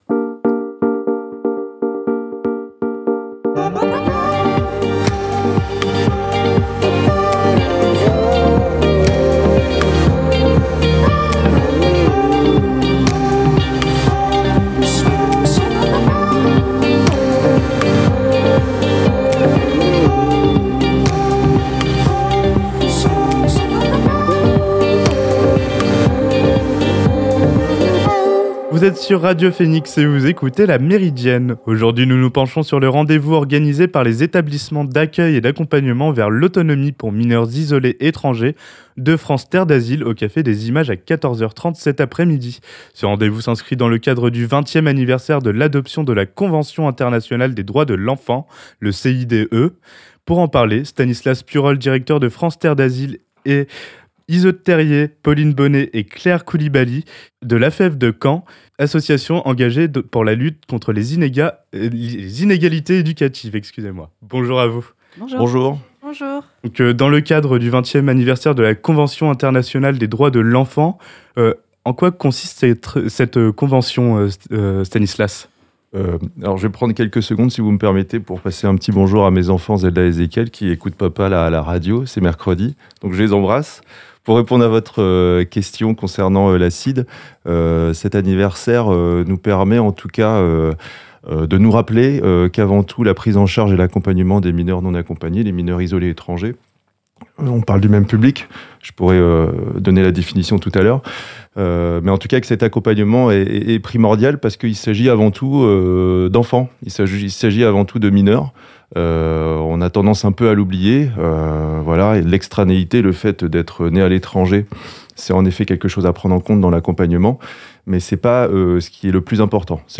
Pause musicale